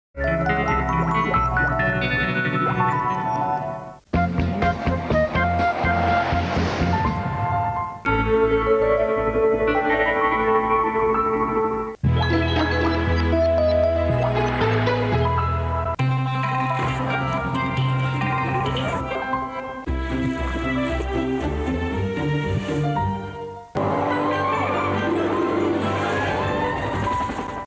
Les vidéos viennent de VHS numérisées par mes soins.